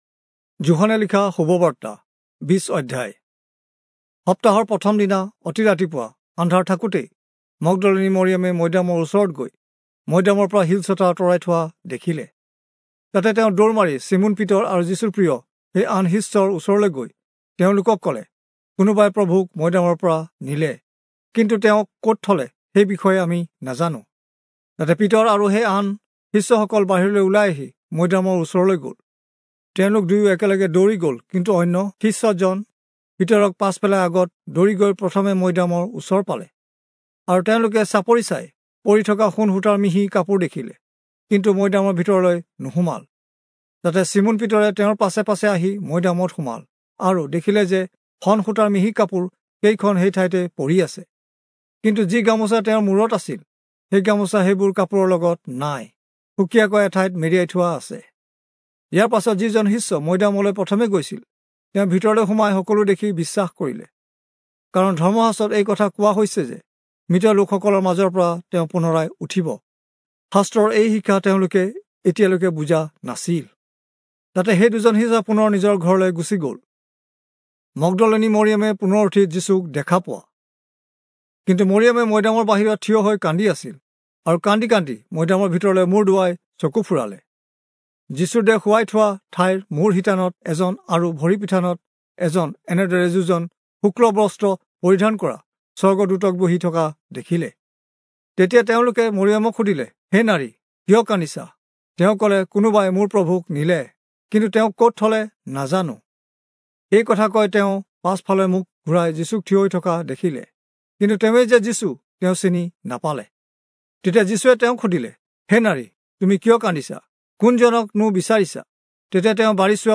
Assamese Audio Bible - John 2 in Orv bible version